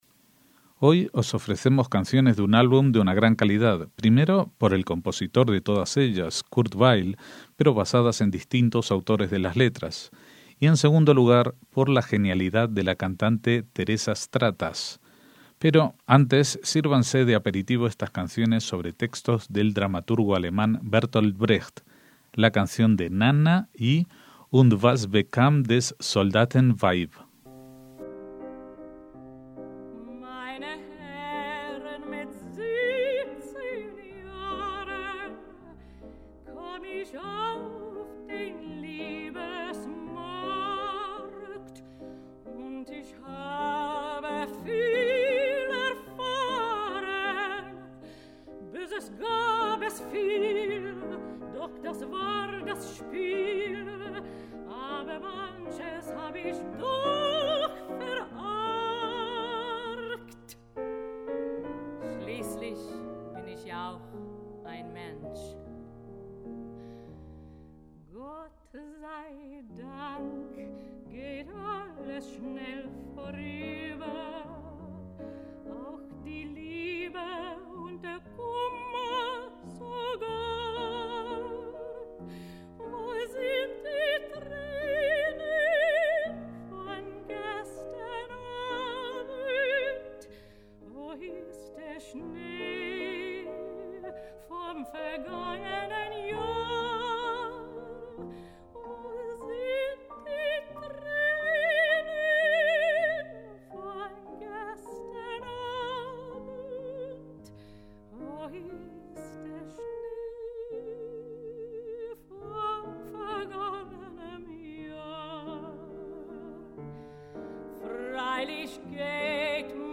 MÚSICA CLÁSICA
soprano
piano